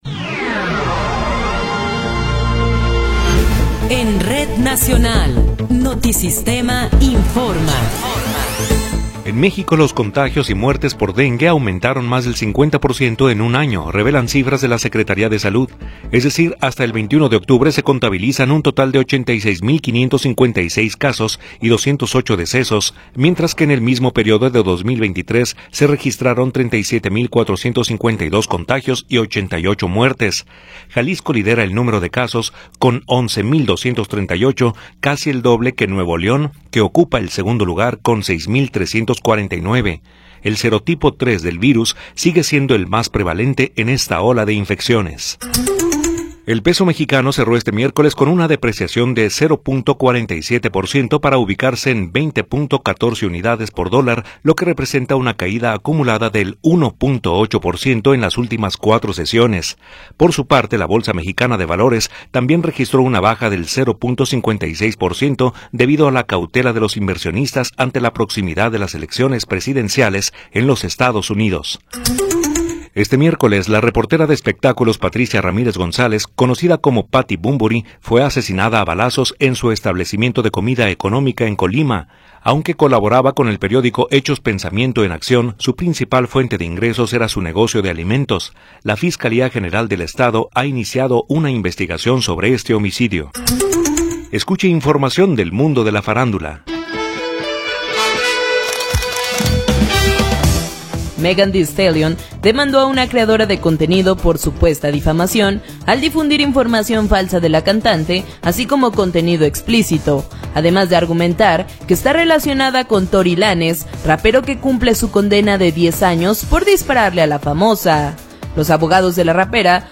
Noticiero 19 hrs. – 30 de Octubre de 2024